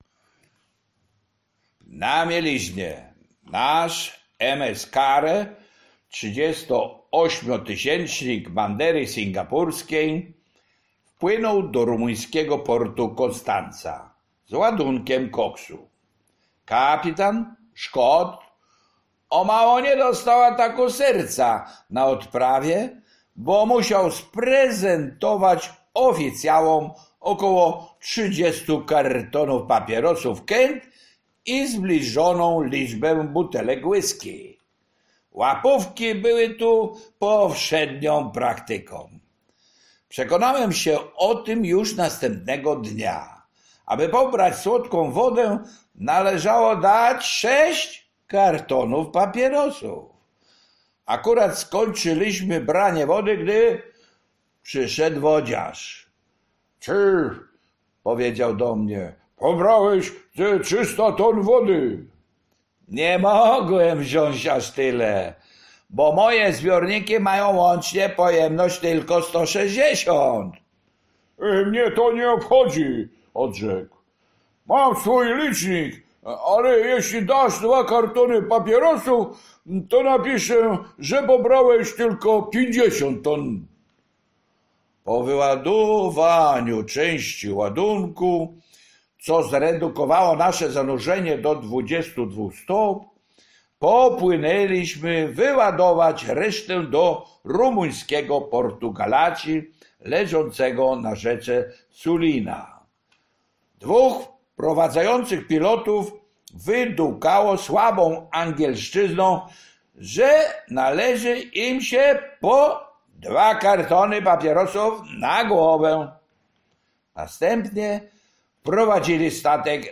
Życie marynarskie (audiobook). Rozdział 16 - Na mieliźnie - Książnica Pomorska